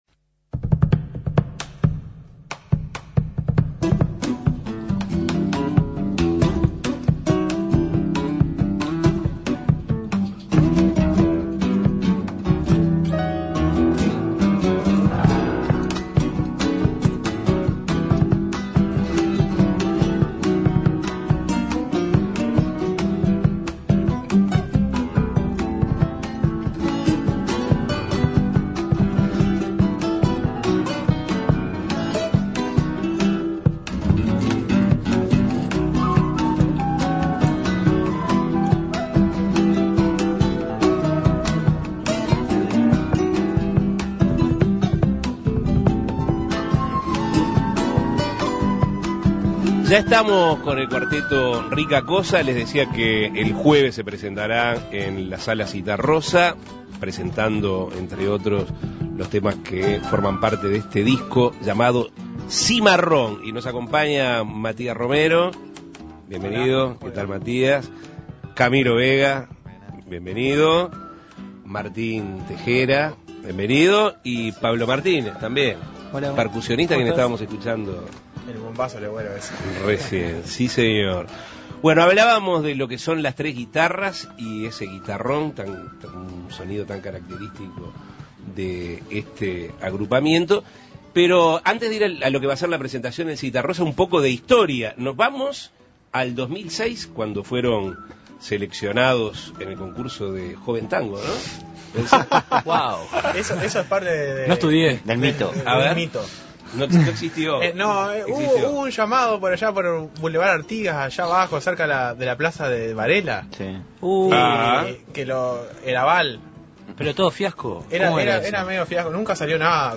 Hoy fueron entrevistados por Asuntos Pendientes.